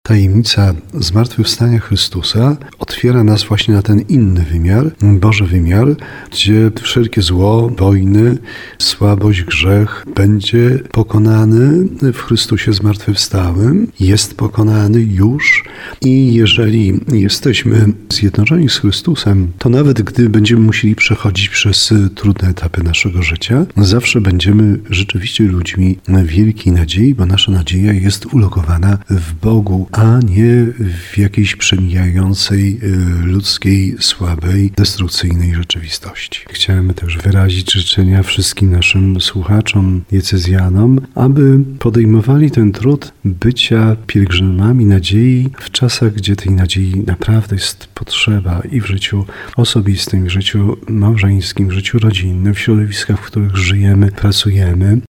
Zmartwychwstanie Jezusa jest drogą całej ludzkości – mówi biskup tarnowski Andrzej Jeż.